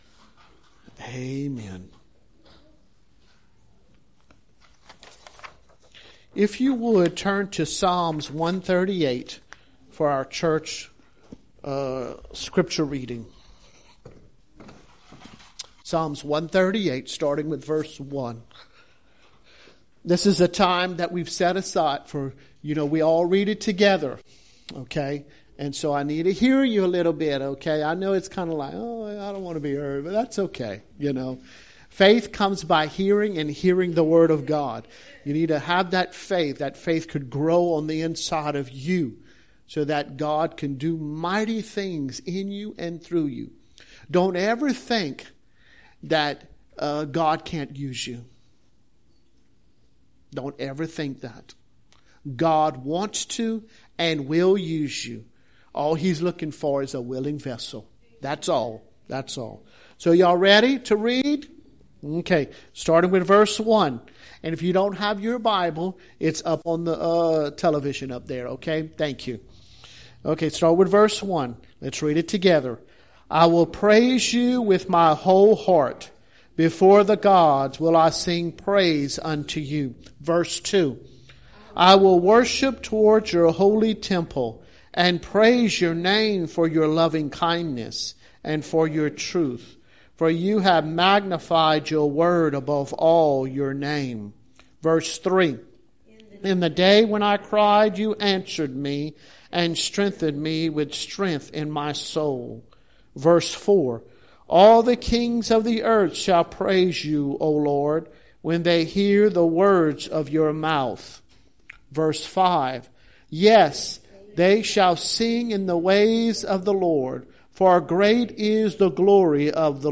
Church Sermons